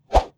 Close Combat Swing Sound 64.wav